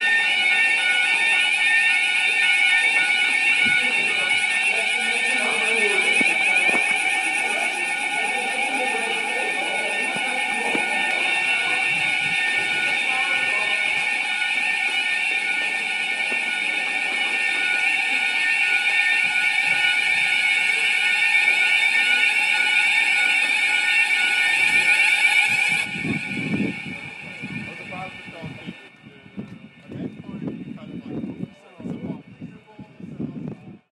火灾报警
描述：自制火灾报警器。这个想法是基于建筑物中的典型火灾报警，并辅以美国紧急车辆的典型（略微调整）声音序列。 使用Audacity完全创建声音和合成。
标签： 紧急 报警 火灾报警 报警信号
声道立体声